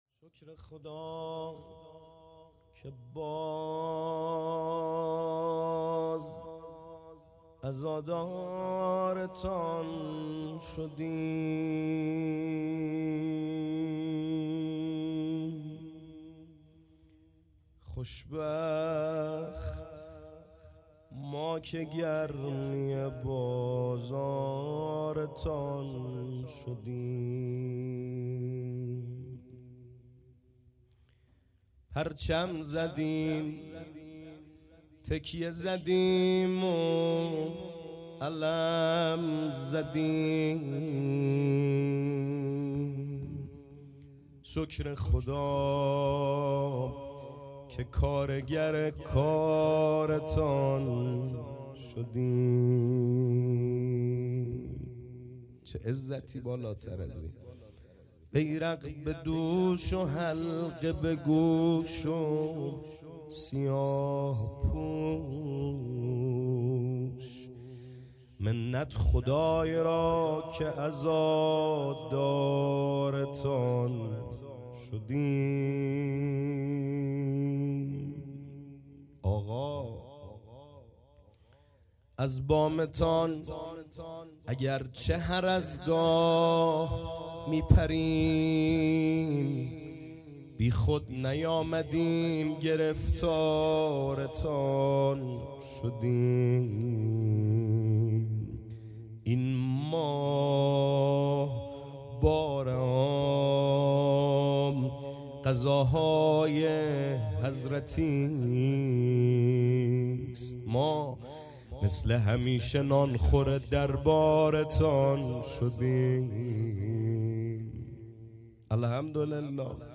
گزارش صوتی شب سوم محرم 98 | هیأت محبان حضرت زهرا سلام الله علیها زاهدان
روضه